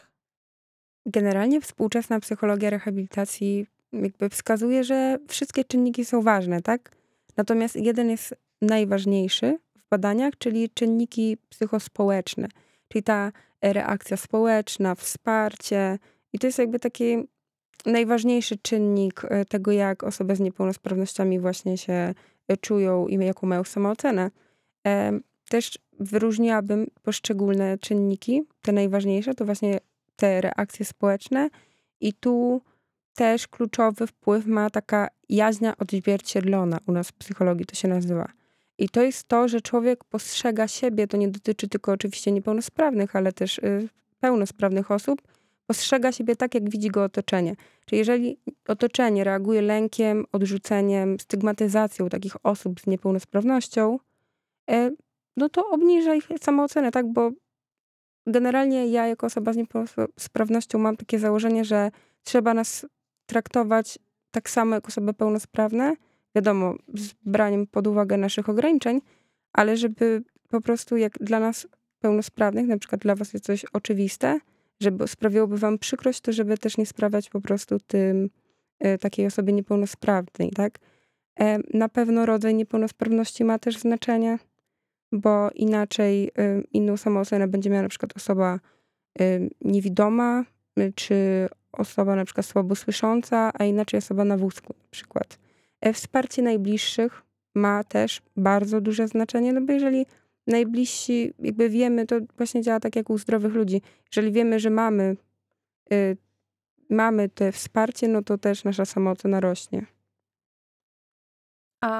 wywiad.mp3